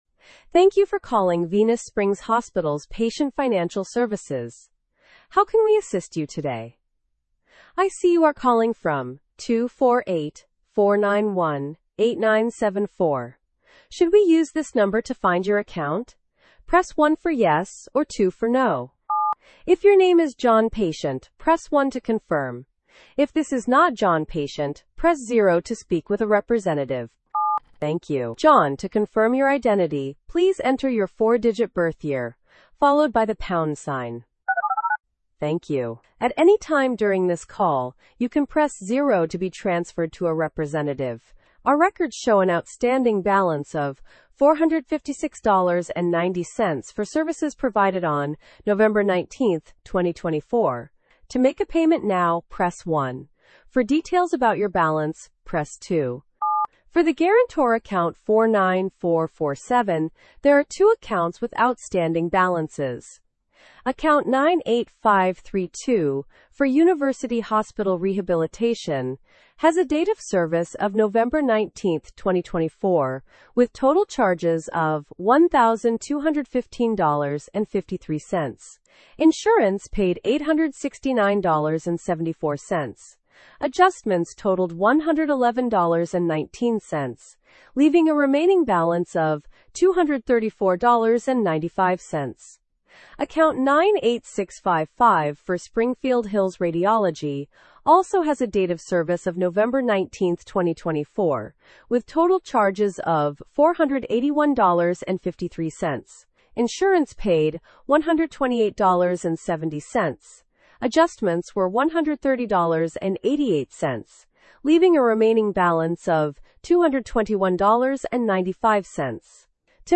Digital Agent Sample Call